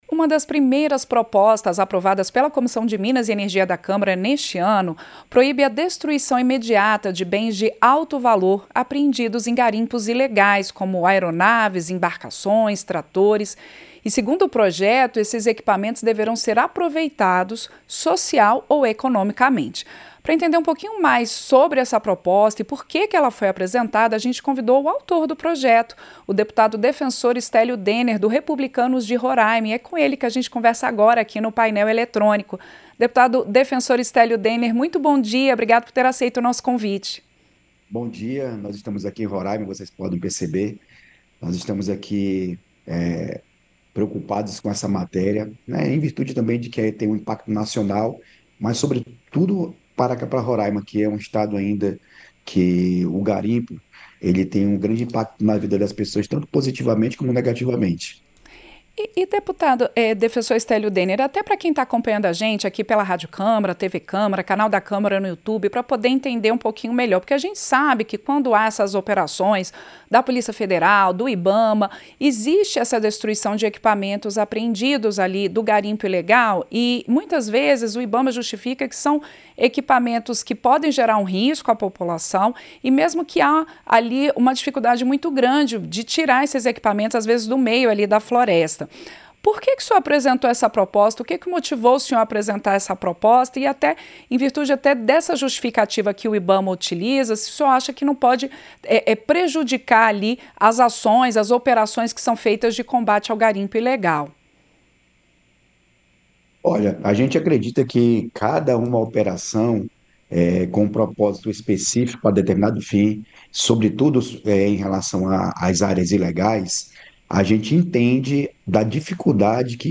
Entrevista - Dep. Defensor Stélio Dener (Republicanos/RR)